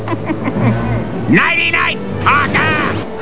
From Spider-Man: The Animated Series.